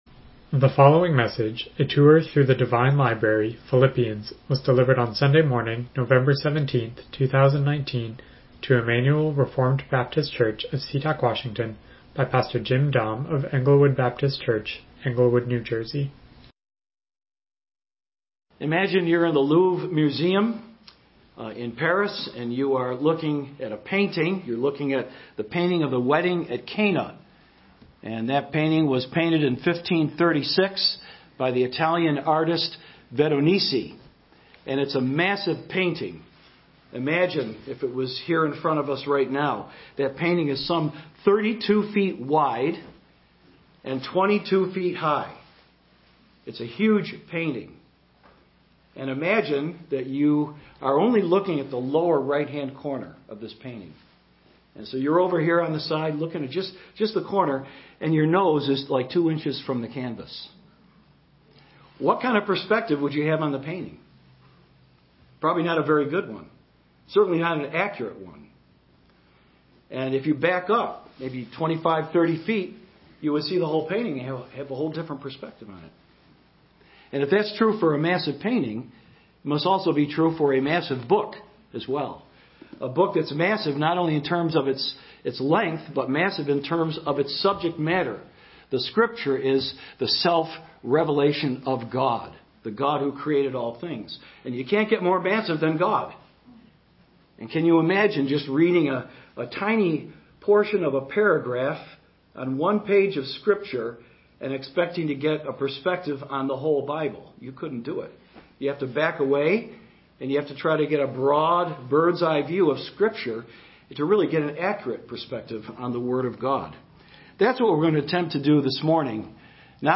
Miscellaneous Service Type: Morning Worship « Qualifications for Elders The Holy Spirit